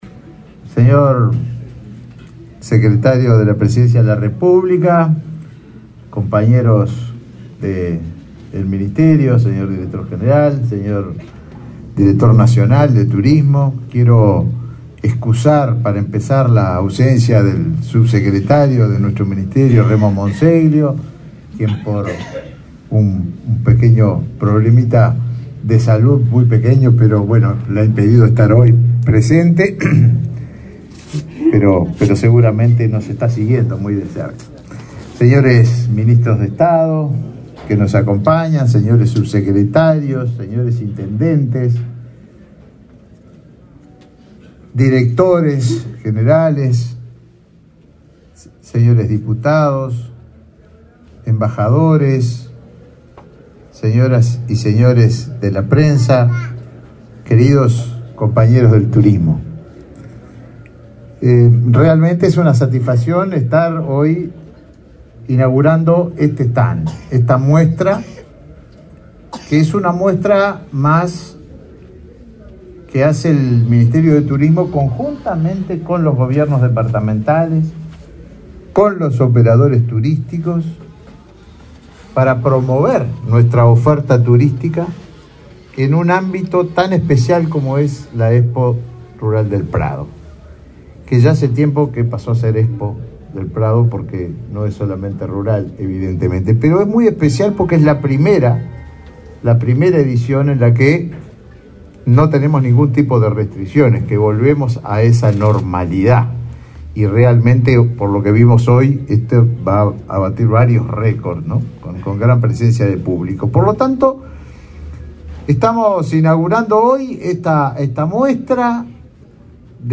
Palabras del ministro de Turismo, Tabaré Viera, en inauguración de stand en la Expo Prado 2022